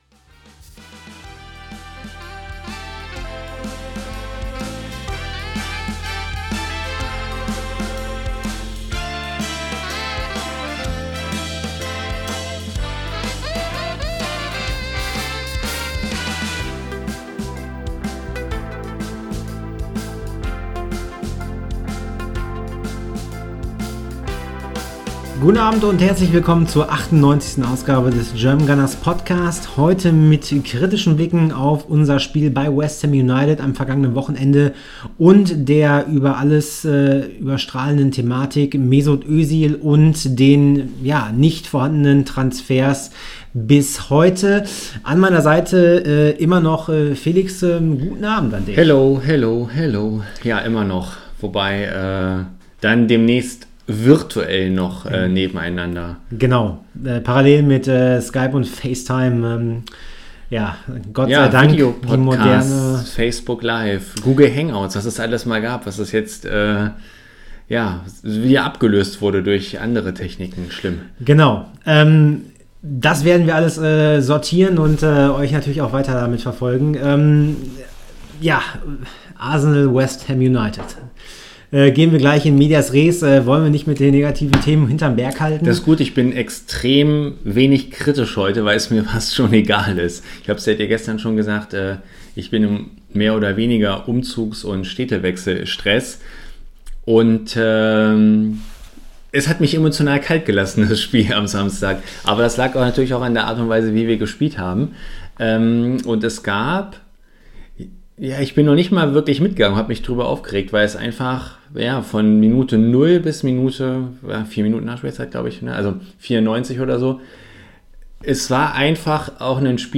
Alles kein wirklicher Grund zur Freude, sodass wir uns entschlossen haben alle diese Themen in einem fließenden Dialog anzugehen und um Antworten zu ringen.